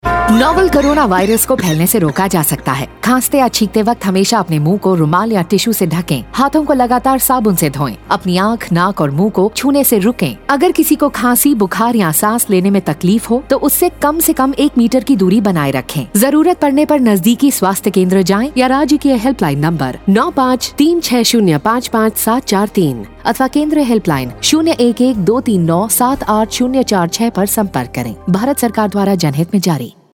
Radio PSA
5139_Cough Radio_Hindi_Arunchal Pradesh.mp3